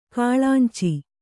♪ kāḷānci